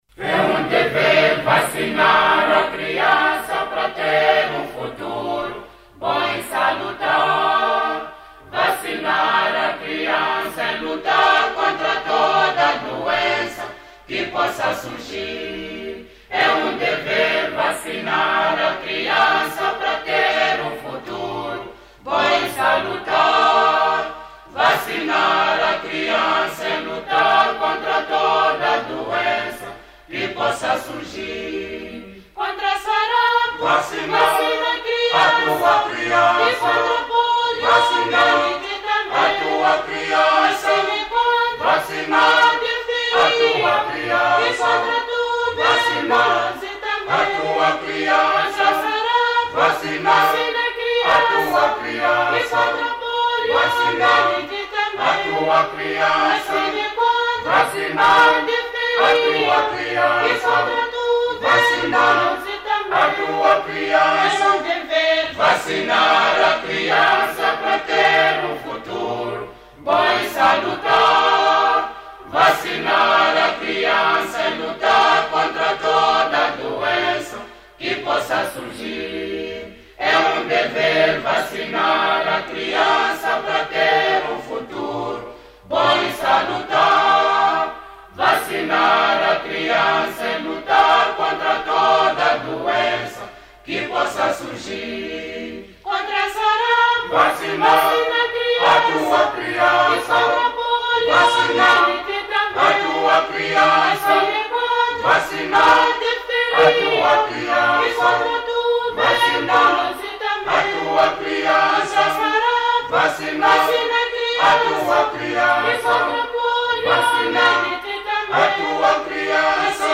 Coro